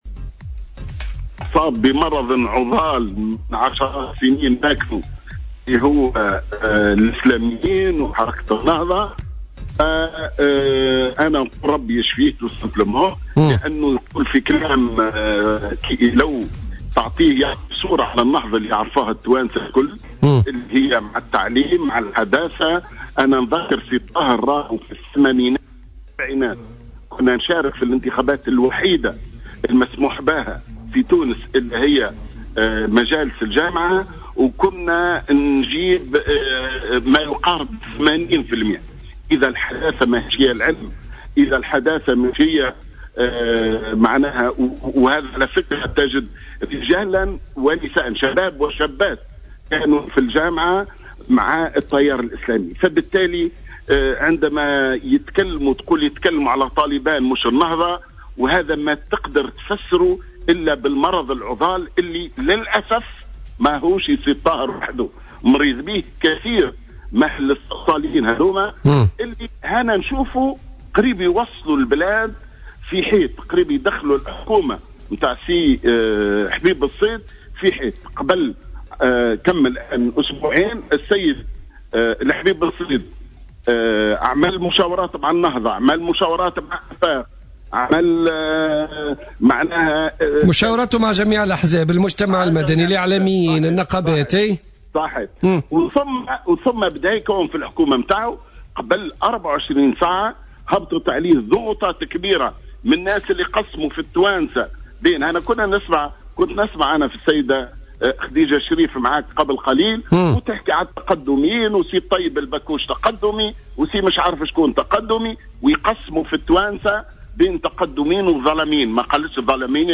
علّق القيادي في حزب حركة النهضة،محمد بن سالم ضيف برنامج بوليتيكا اليوم على...